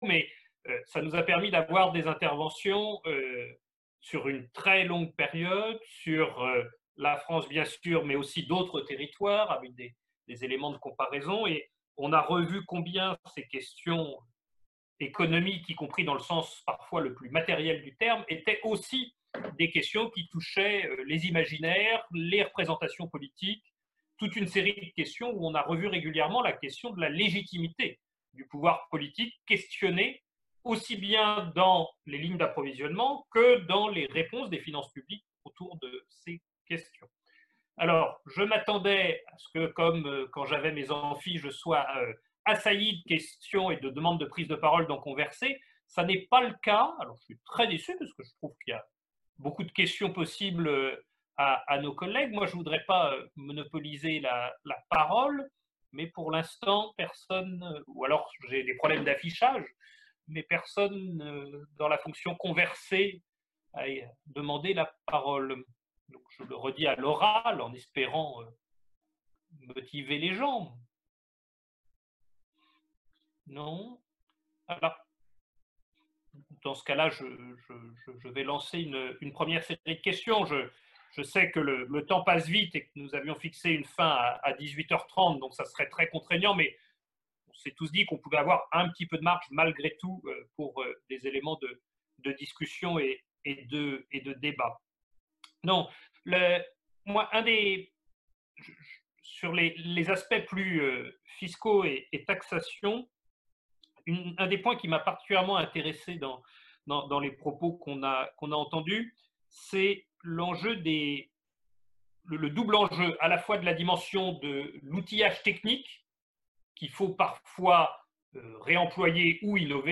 Webinaire AFHE n°1. 7/7. Débat sur Finances publiques et approvisionnement en sortie de crise | Canal U